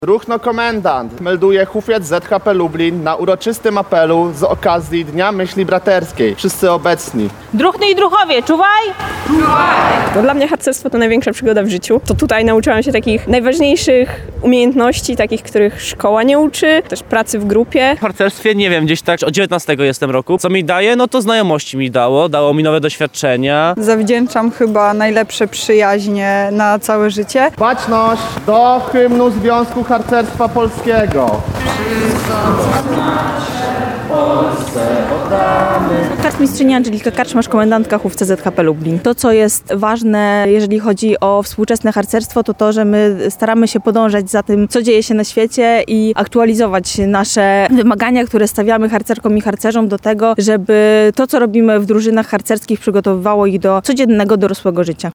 POSŁUCHAJ DŹWIĘKOWEJ RELACJI:
W Lublinie uroczystości z okazji Dnia Myśli Braterskiej odbyły się na dziedzińcu zamku lubelskiego.